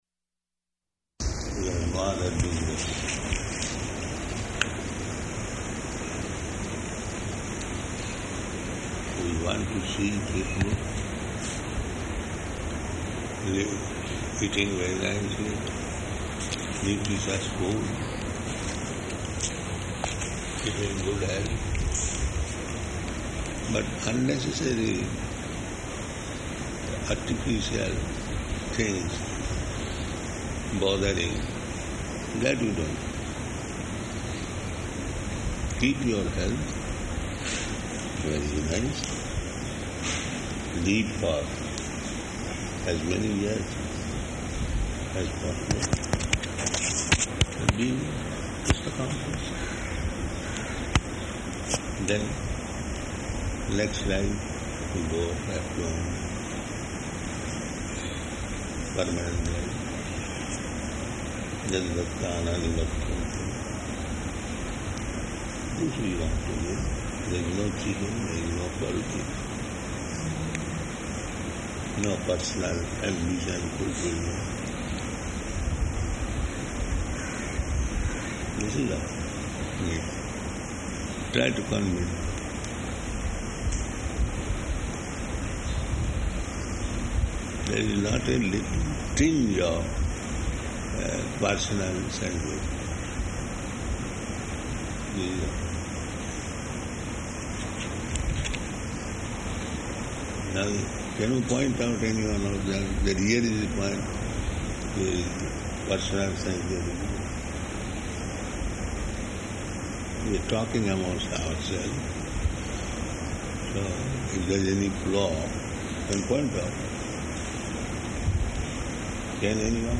Conversation Pieces --:-- --:-- Type: Conversation Dated: May 27th 1977 Location: Vṛndāvana Audio file: 770527R2.VRN.mp3 Prabhupāda: We have no other business.